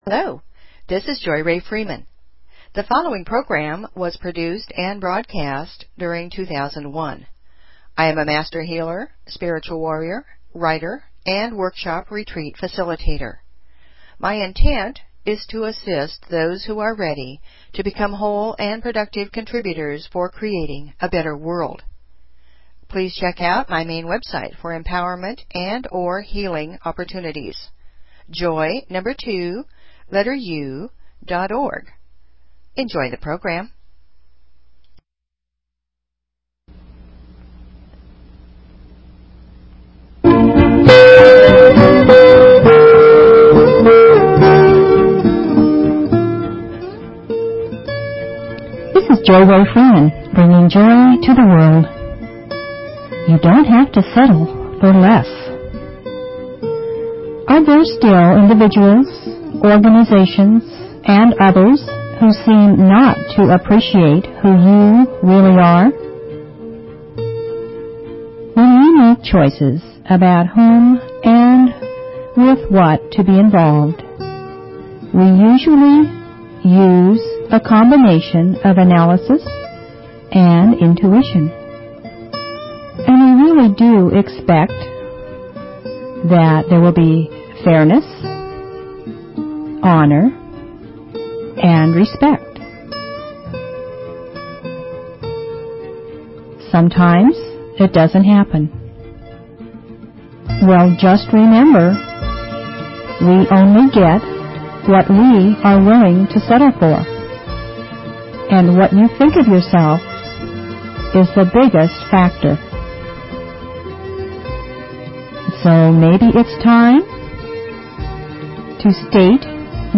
Talk Show Episode, Audio Podcast, Joy_To_The_World and Courtesy of BBS Radio on , show guests , about , categorized as
JOY TO THE WORLD - It's a potpourri of music, INSPIRATION, FOLKSY FILOSOPHY, POETRY, HUMOR, STORY TELLING and introductions to people who are making a difference. It's lively, but not rowdy - it's sometimes serious, but not stuffy - it's a little funny, but not comical - and most of all - it's a passionate, sincere sharing from my heart to yours.